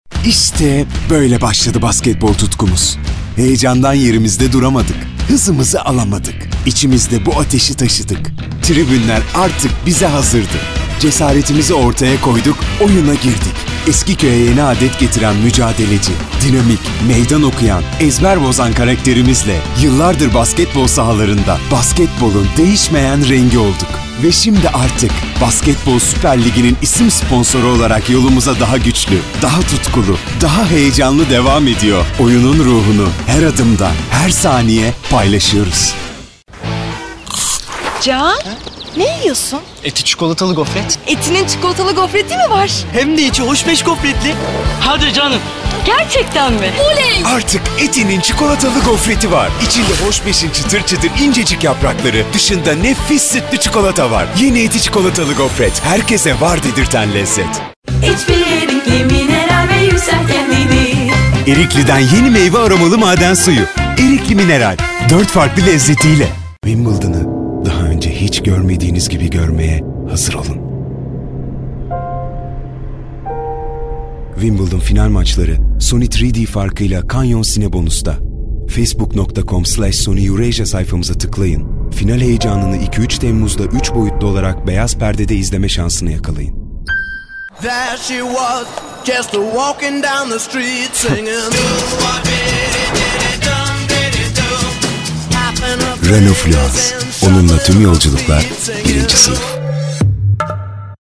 Çeşitli reklamlarda seslendirme yapmıştır.